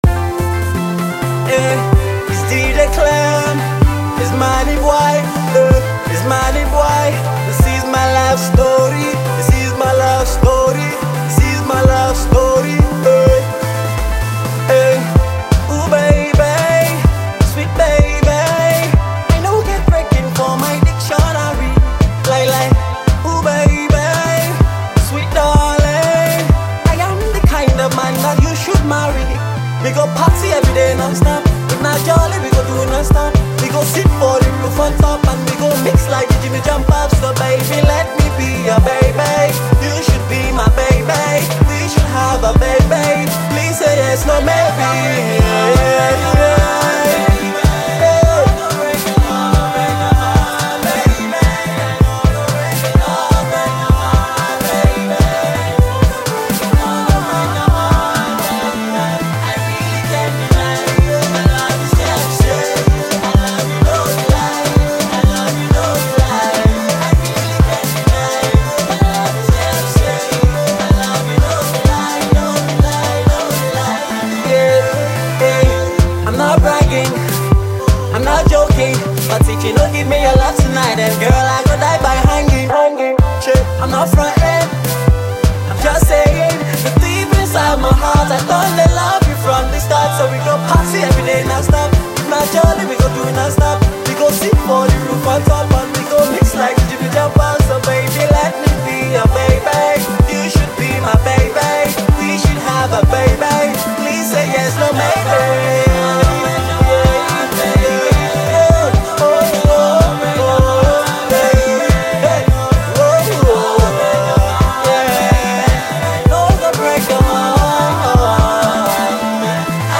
a high energy song